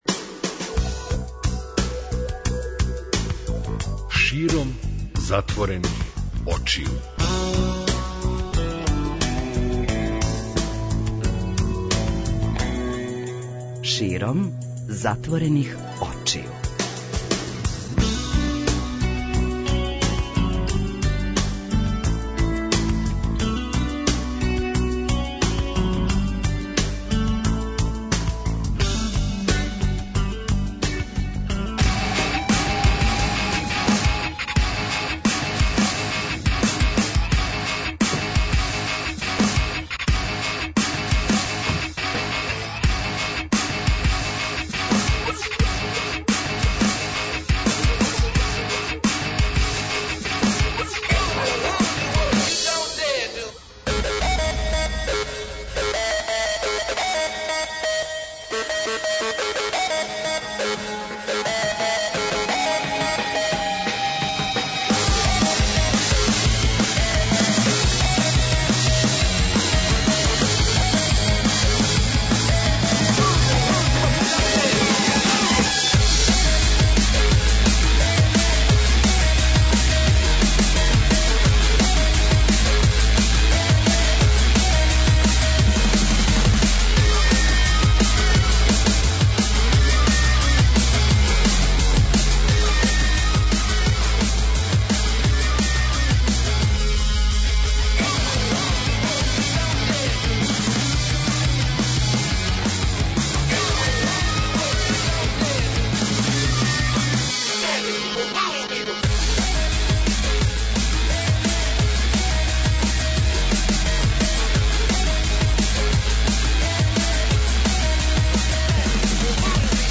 Ноћни програм Београда 202